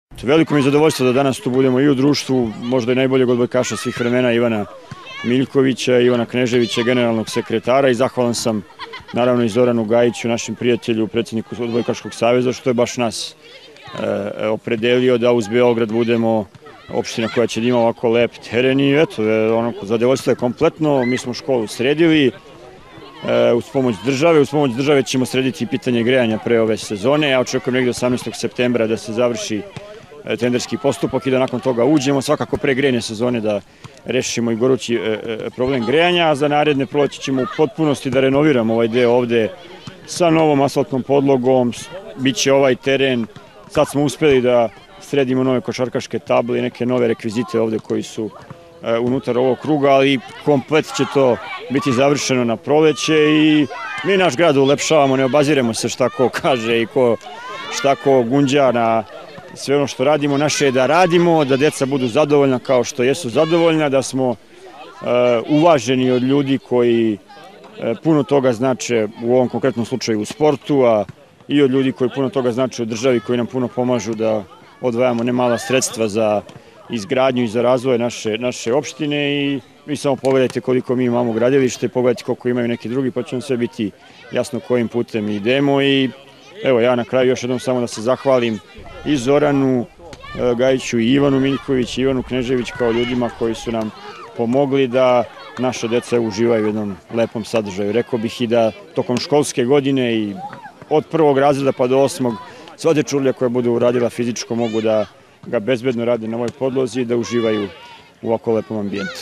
IZJAVA DARKA GLIŠIĆA